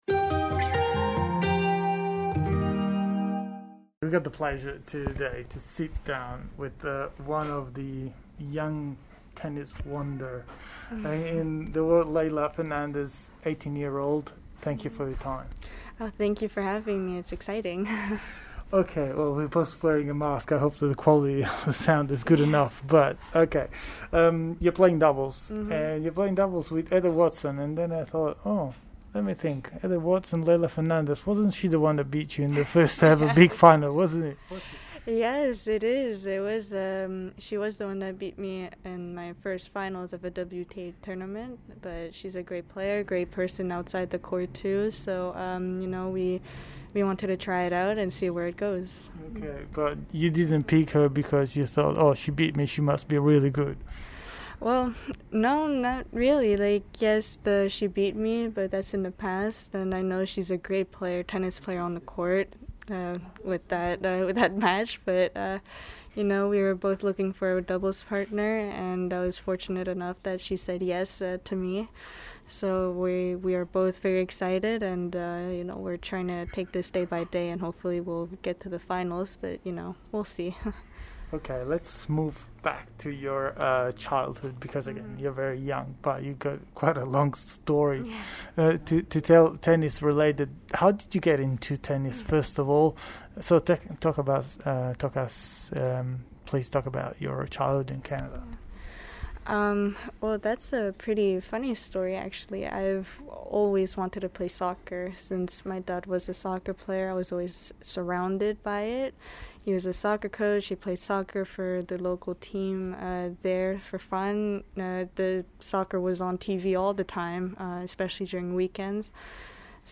In this interview, Leylah talks about her very multicultural childhood, how and why she chose tennis over soccer and express her goal to reach the world's top 10 by the end of the year.